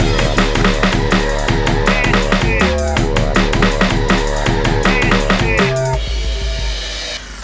32 Hardcore-c.wav